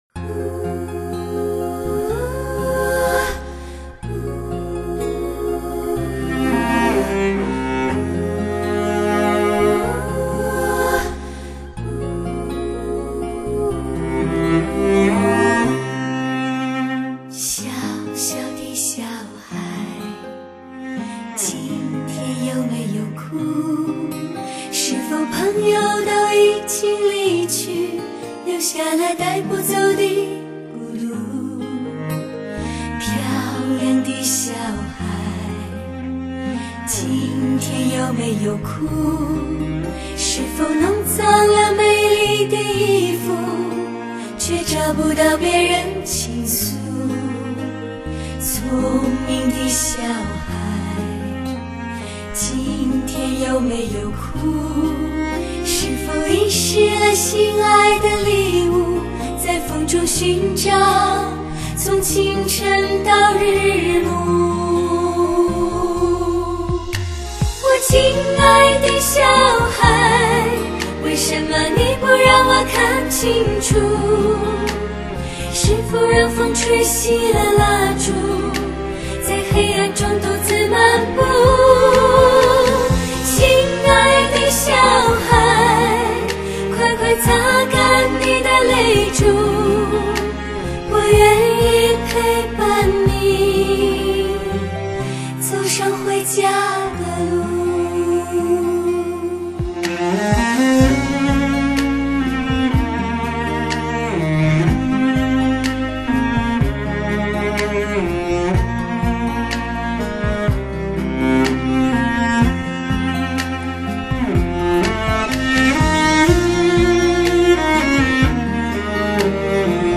大提琴低沉、忧伤